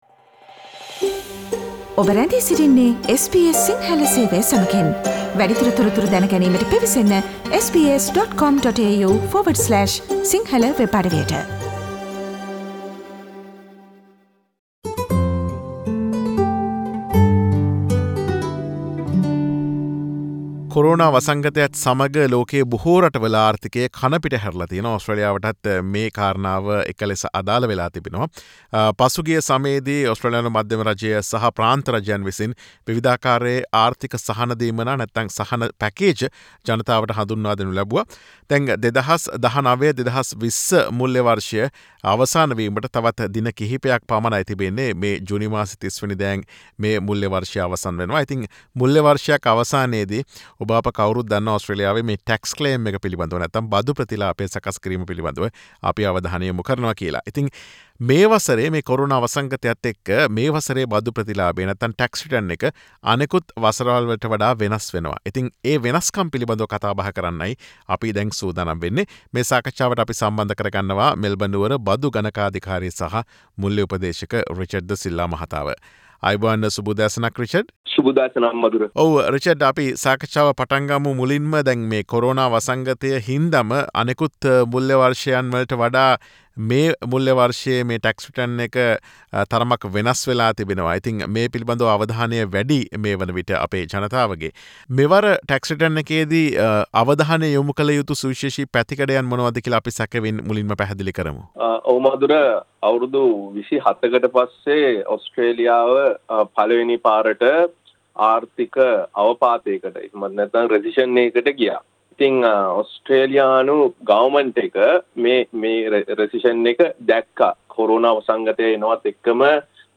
SBS සිංහල ගුවන් විදුලිය සිදු කළ සාකච්ඡාව.